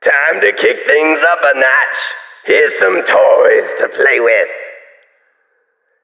🌲 / midnight_guns mguns mgpak0.pk3dir sound announcer